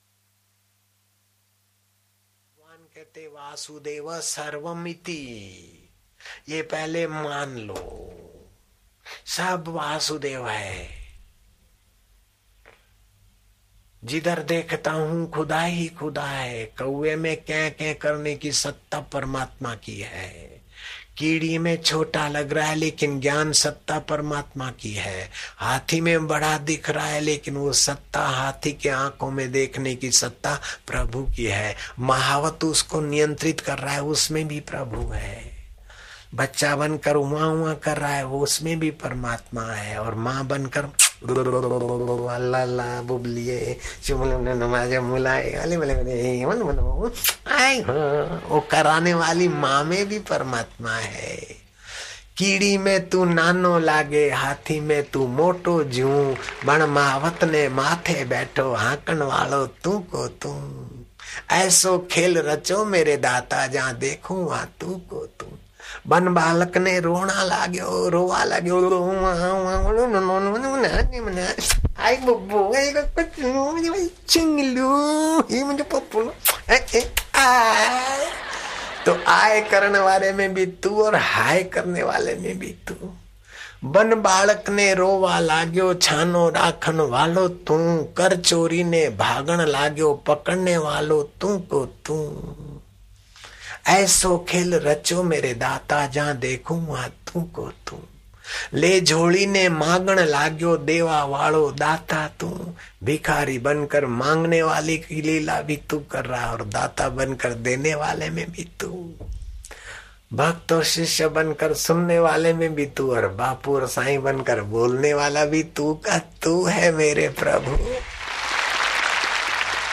Audio Satsang MP3s of Param Pujya Sant Shri Asharamji Bapu Ashram